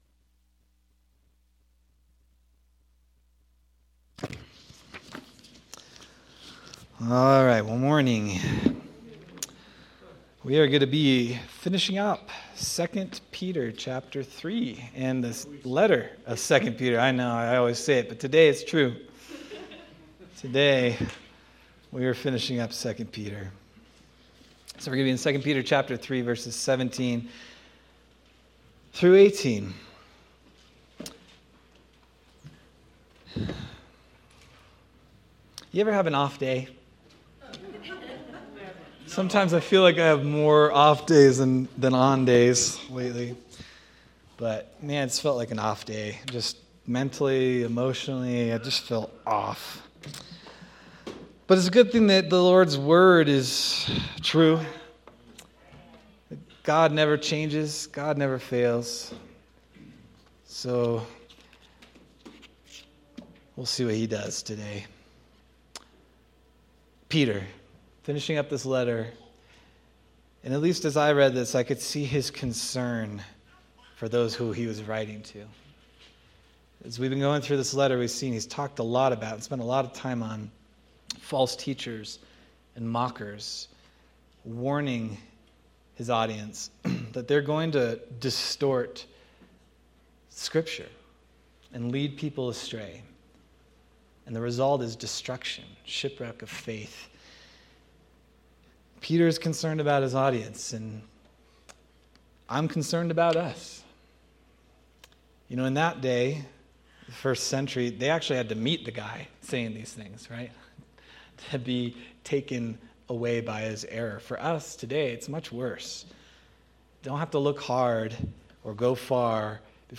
December 28th, 2025 Sermon